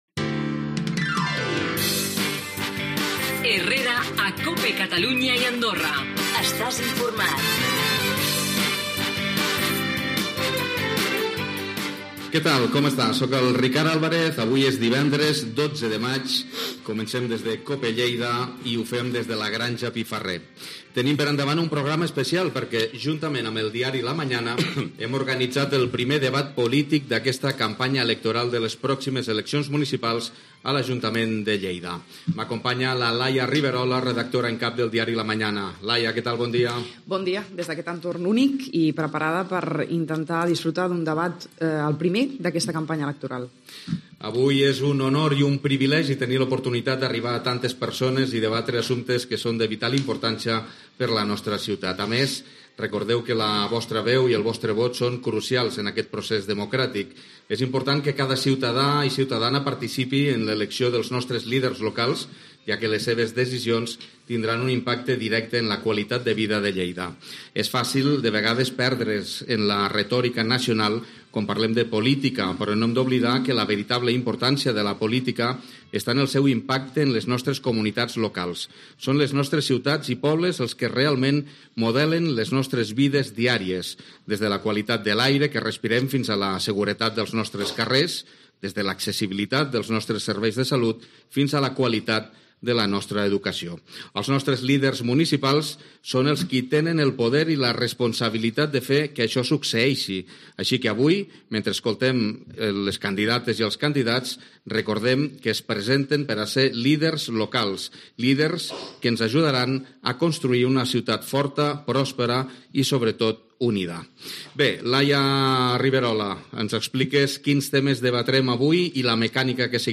Indicatiu del programa, presentació del debat electoral a les eleccions municipals de Lleida, presentació, blocs dels debat, candidats que participen
Informatiu
FM